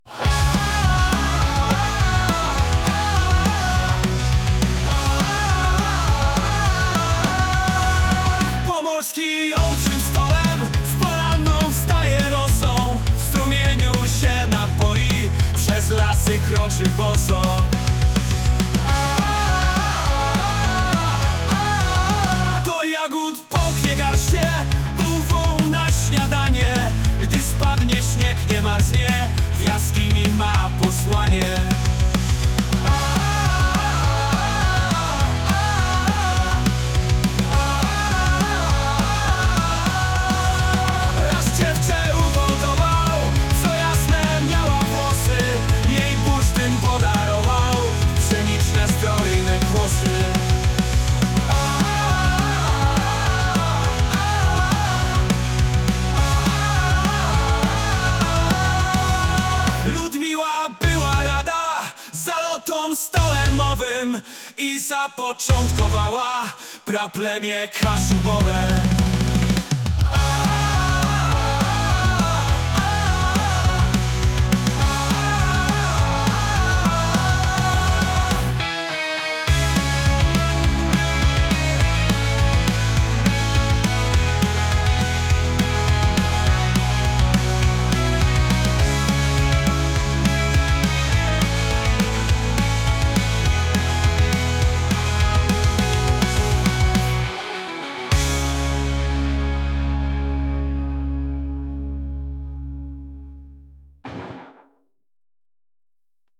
Podkład muzyczny tytuł: Stolemowe dzieci , autor: inteligencja Sztuczna Odsłuchań/Pobrań 1
Nagranie wykonania utworu